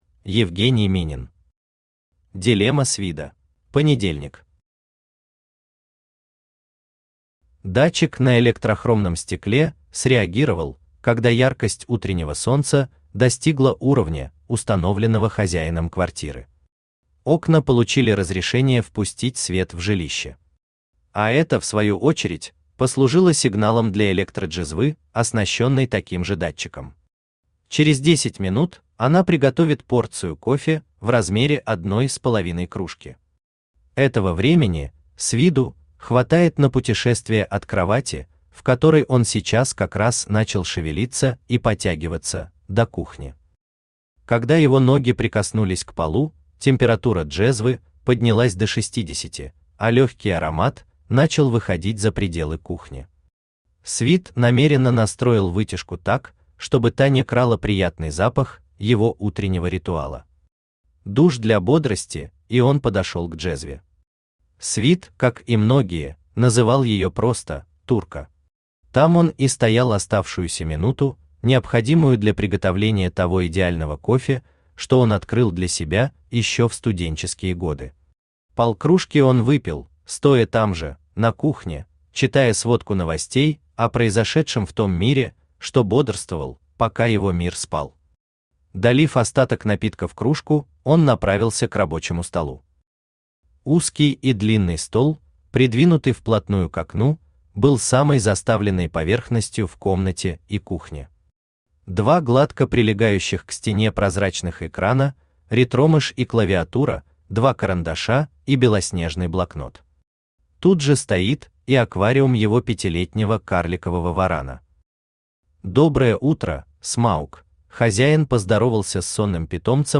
Аудиокнига Дилемма Свида | Библиотека аудиокниг
Aудиокнига Дилемма Свида Автор Евгений Александрович Минин Читает аудиокнигу Авточтец ЛитРес.